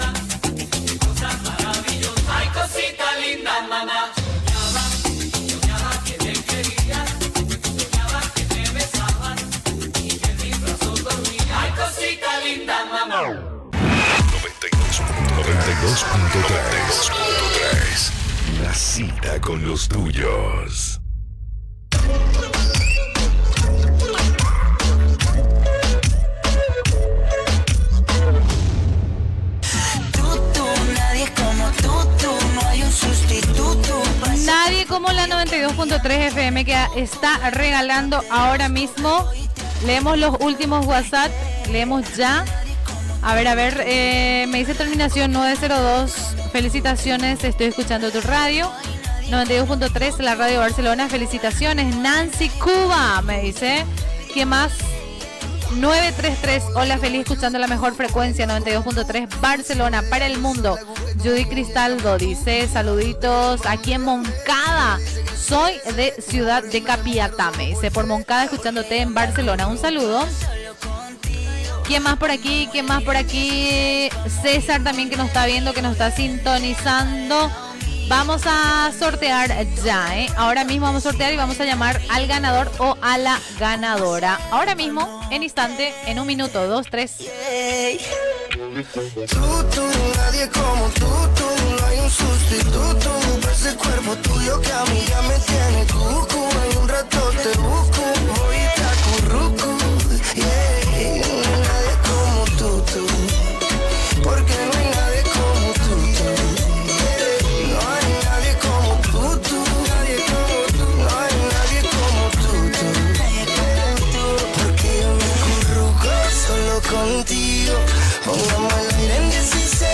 Indicatiu de la ràdio, "whats app" rebuts i salutacions, tema musical, sorteig, indicatiu de la ràdio